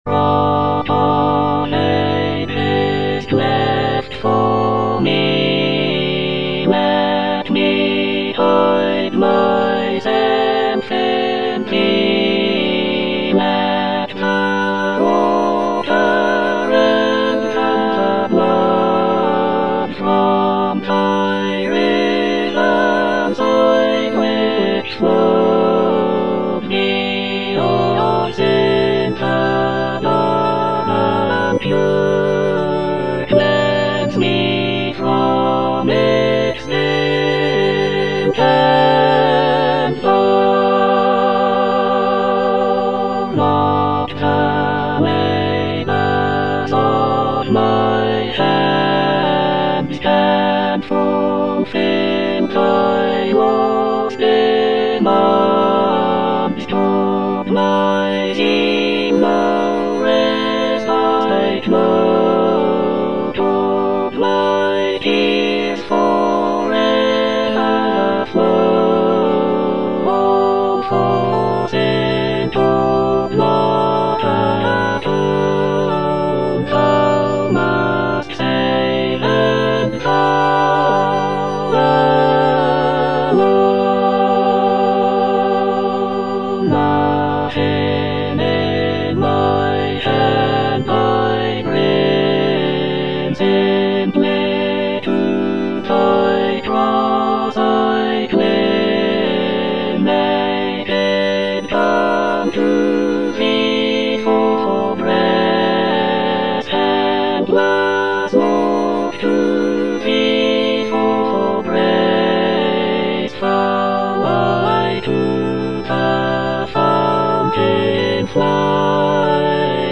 Rock of ages, cleft for me (All voices) Ads stop: auto-stop
"Olivet to Calvary" is a sacred cantata composed by John Henry Maunder in 1904.
"Olivet to Calvary" is known for its lyrical melodies, dramatic choral writing, and poignant orchestration.